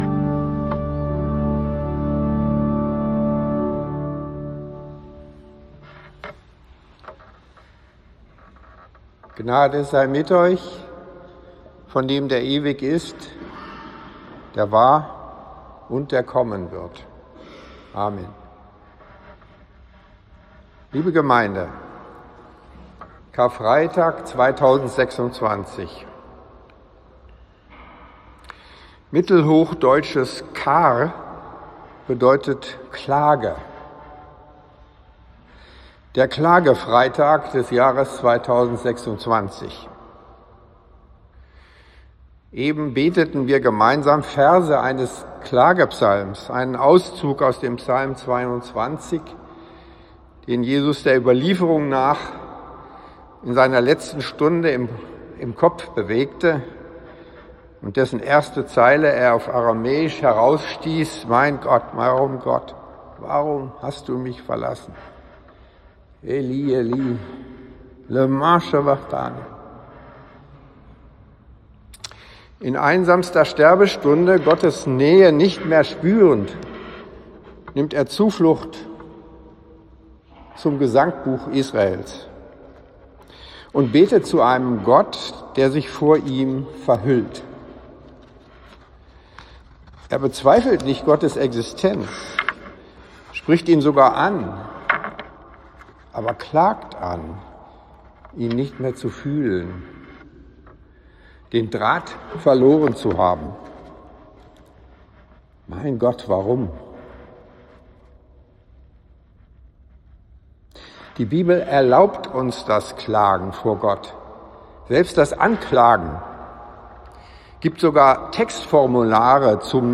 Mein Gott, warum - ? (Psalm 22) Predigt über Psalm 22 zum Download .
2026-4-karfreitag-predigt.m4a